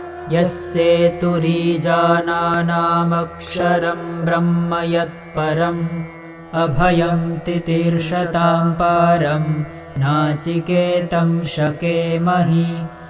Mantra
KathaUV13M2_mantra.mp3